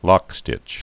(lŏkstĭch)